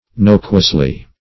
nocuously.mp3